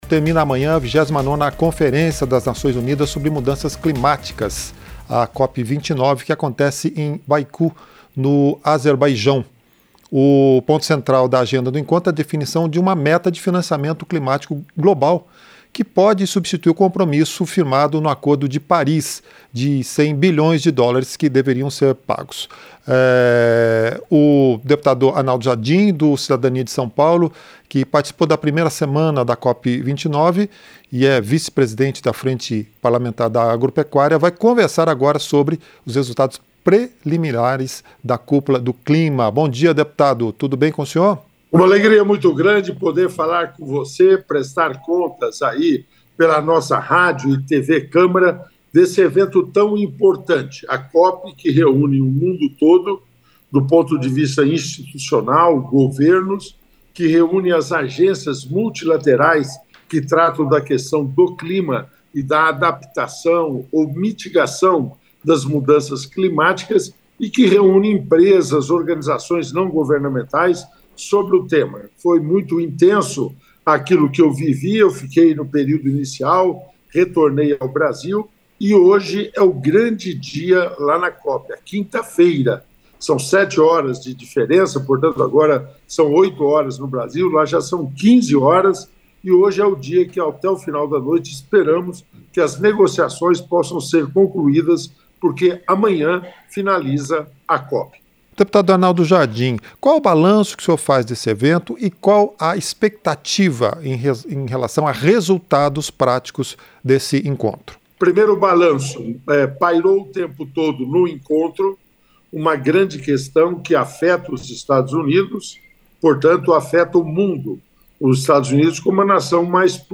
• Entrevista - Dep. Arnaldo Jardim (Cidadania-SP)
Programa ao vivo com reportagens, entrevistas sobre temas relacionados à Câmara dos Deputados, e o que vai ser destaque durante a semana.